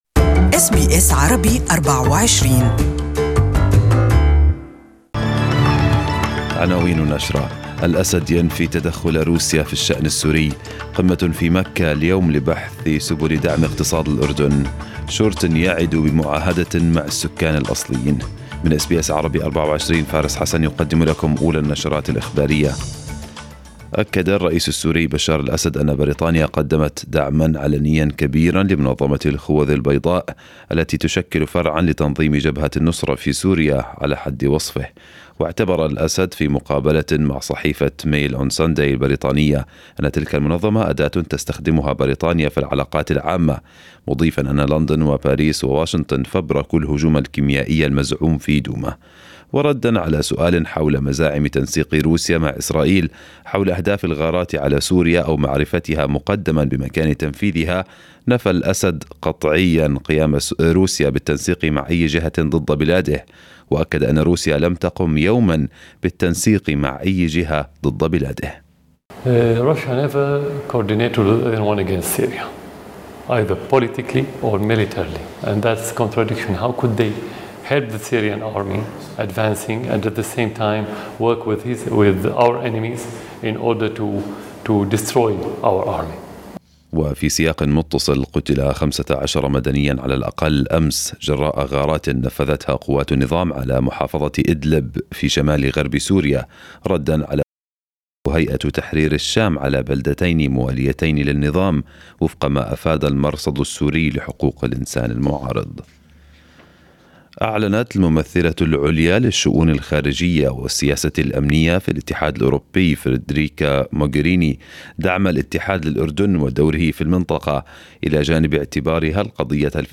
Arabic News Bulletin 11/06/2018